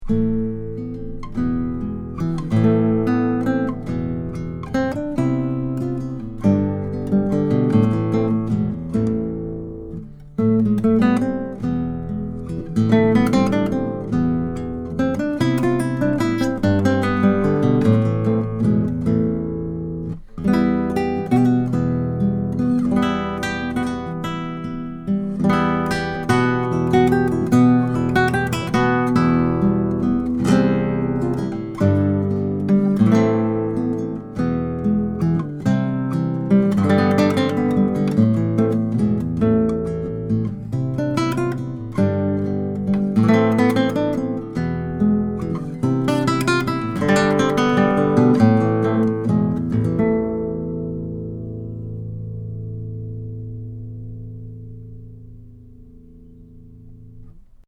* Polar Pattern: Omni
This mic sounds wonderful, with low self-noise, and I used it briefly for tracking Classical Harp Guitar, and it sounds warm, detailed and very musical.
Here are sixteen quick, 1-take MP3 sound files showing how the mics sound using the KM-83 through a Presonus ADL 600 preamp into a Rosetta 200 A/D converter. No EQ or effects.
BARTOLEX CLASSICAL HARP GUITAR: